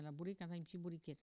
locutions vernaculaires
Catégorie Locution